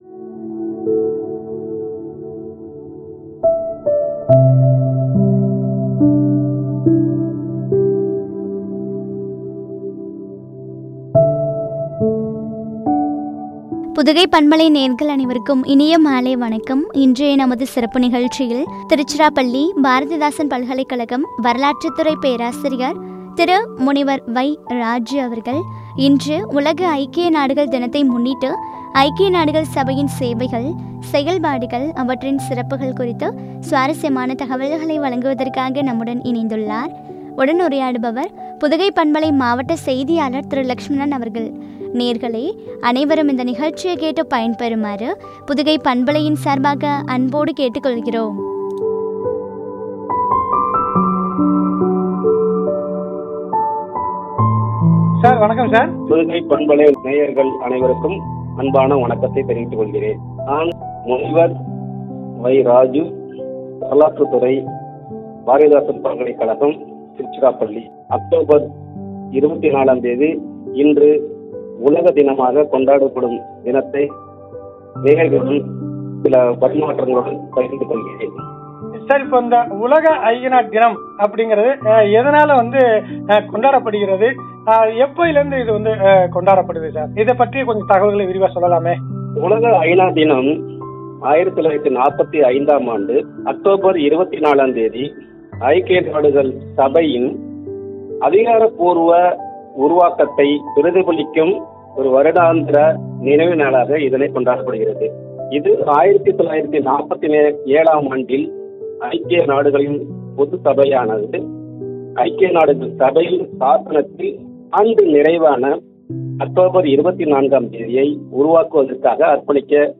சிறப்புகளும்” குறித்து வழங்கிய உரையாடல்.